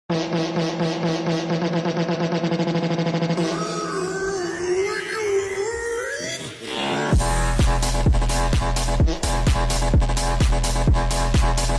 Fart Party Mix Sound Button - Free Download & Play